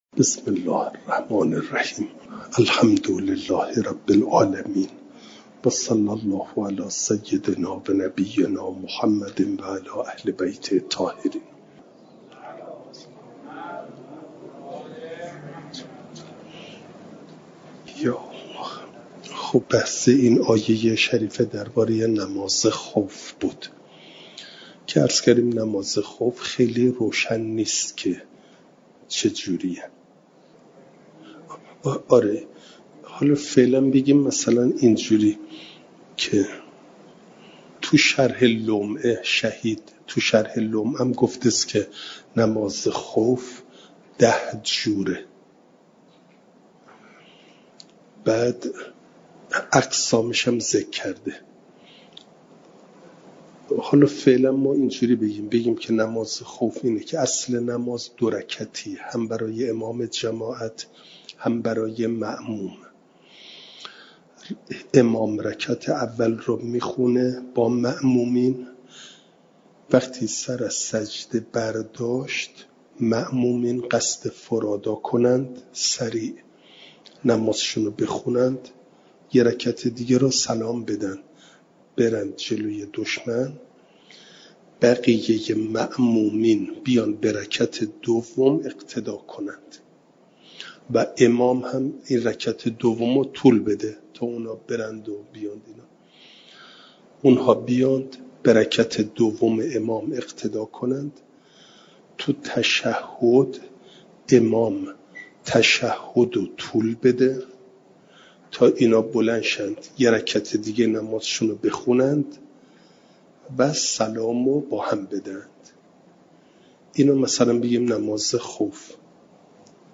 جلسه سیصد و هشتاد و هفتم درس تفسیر مجمع البیان